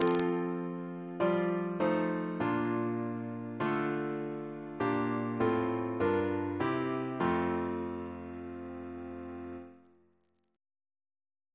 Single chant in F Composer: Sir H. Walford Davies (1869-1941), Organist of the Temple Church and St. George's, Windsor Reference psalters: H1982: S45; RSCM: 215